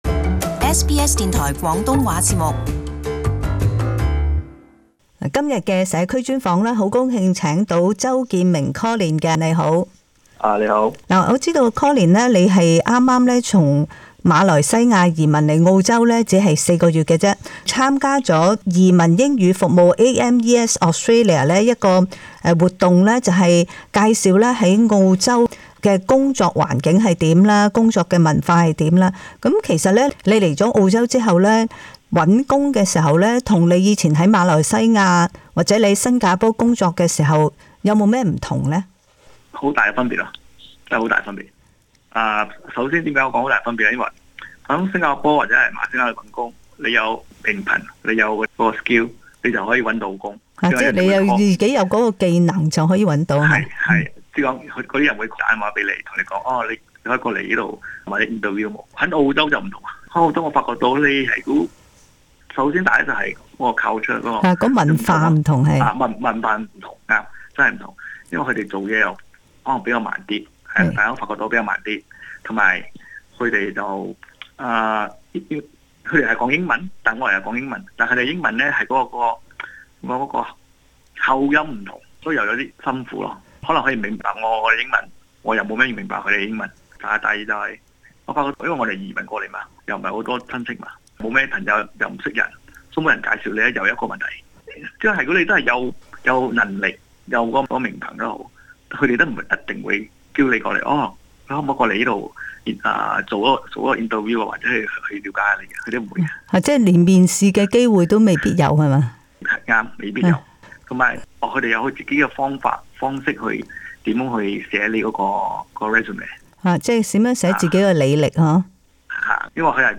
【社區專訪】技術移民如何揾工